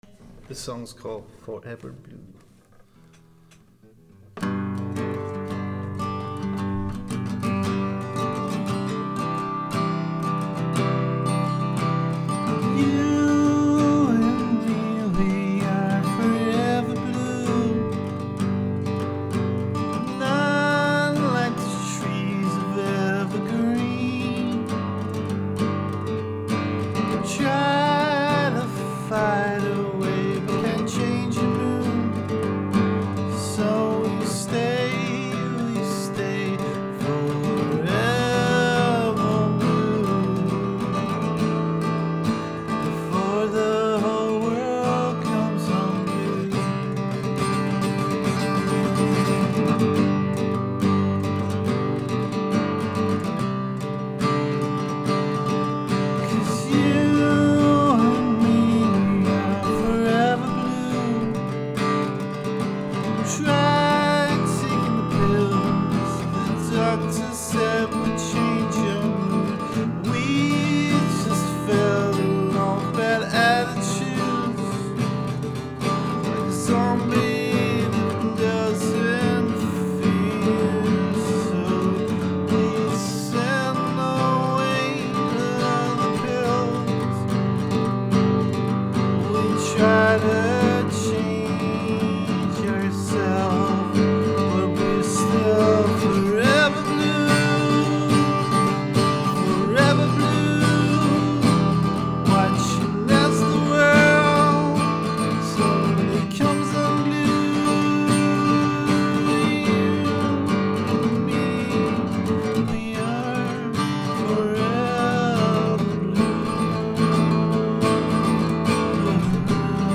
vocal guitar